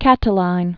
(kătl-īn) Originally Lucius Sergius Catilina. 108?-62 BC.